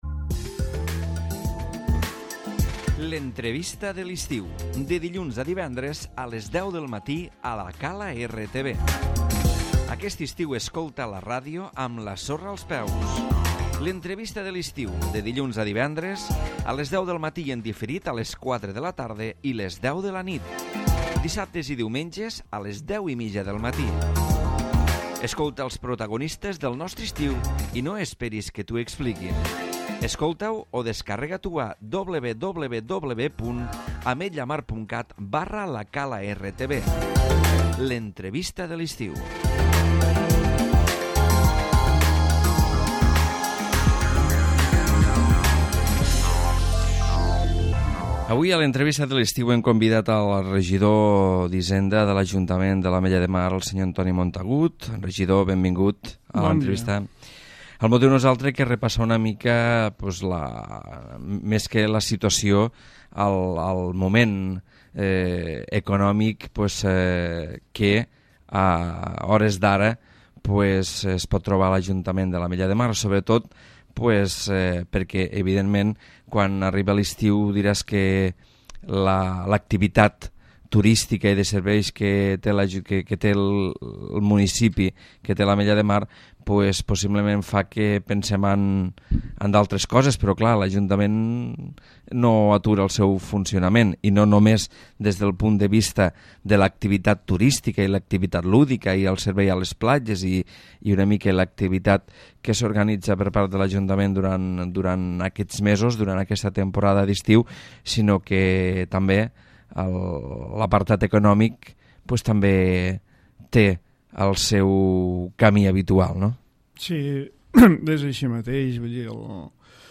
L'Entrevista
Antoni Montagut, regidor d'Hisenda, ens parla de l'últim pagament de l'Ajuntament als proveïdors.